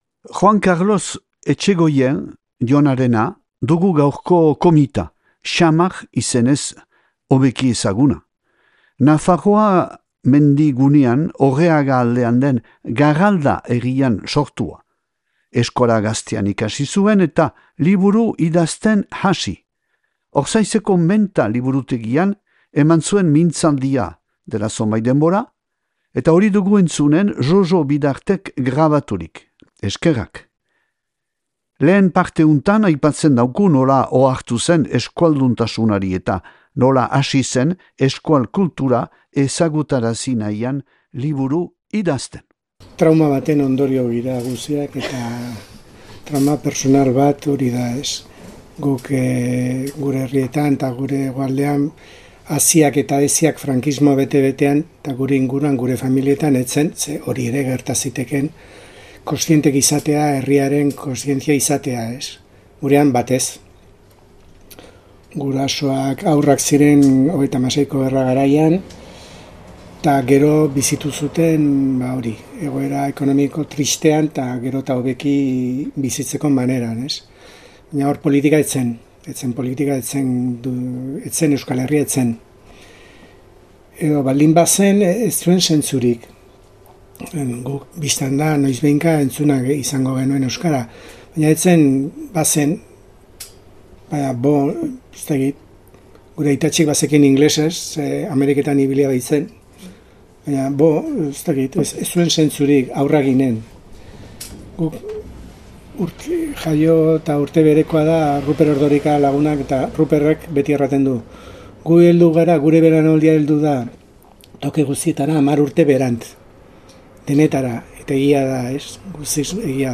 Ortzaize Menta liburutegian eman hitzaldi hunen lehen parteankondatzen dauku nola hasi zen euskaraz idazten.